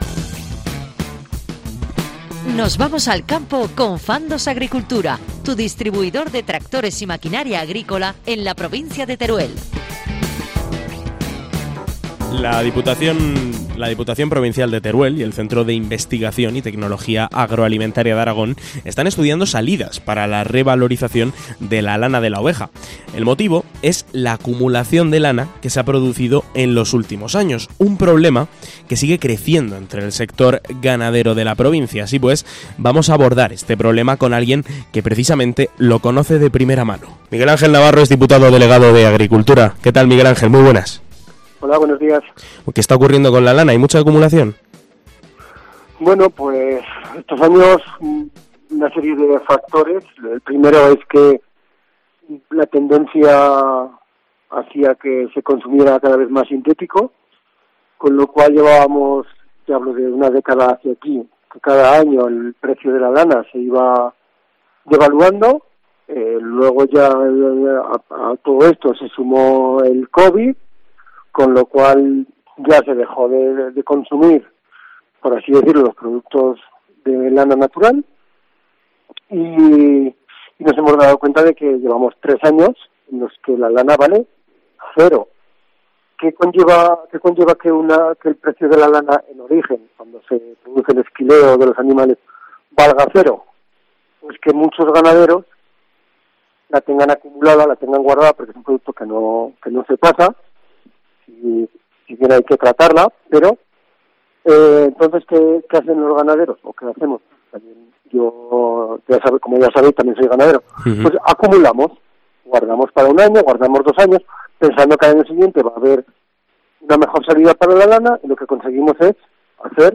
Un ganadero explica qué está pasando con la lana de oveja en Teruel: "Una década y el covid"
Por ello, de la mano de Fandos Agricultura, hemos abordado este problema con Miguel Ángel Navarro, ganadero y diputado delegado de Agricultura y Ganadería de la Diputación Provincial de Teruel.